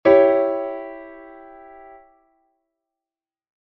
Que tipo de acorde estás a escoitar?